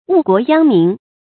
誤國殃民 注音： ㄨˋ ㄍㄨㄛˊ ㄧㄤ ㄇㄧㄣˊ 讀音讀法： 意思解釋： 給國家人民帶來禍害。